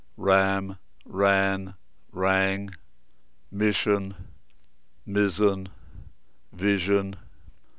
consonants2.aiff